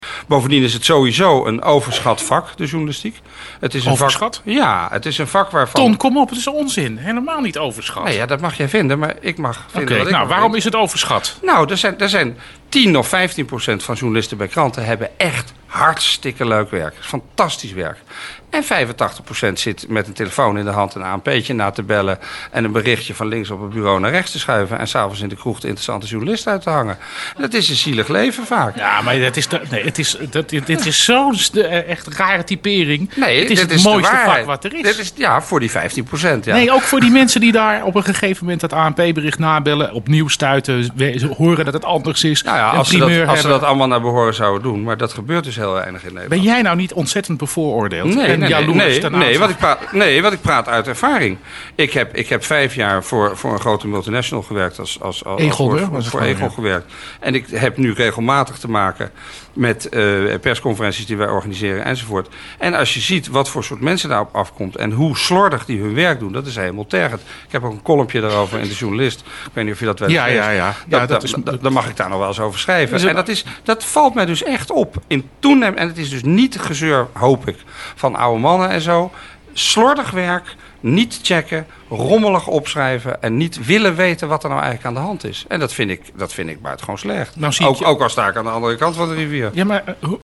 Merkwaardig hoe een interview van Theodor Holman met Ton Elias [mp3] over het kwaad in de wereld binnen de kortste keren uitmondt in een litanie over de kwaliteit van de journalistiek.